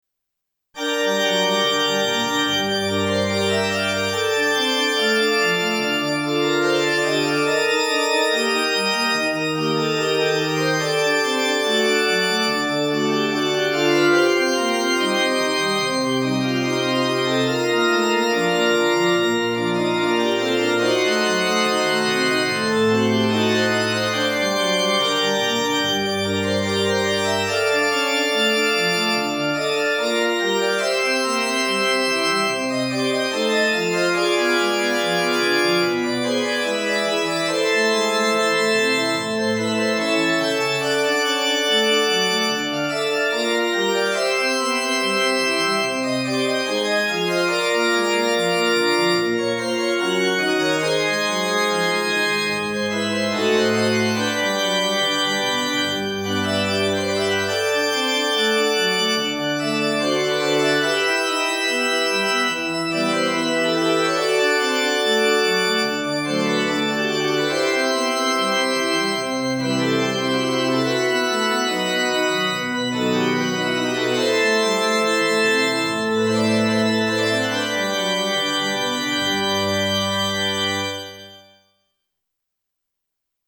at Misakicho Church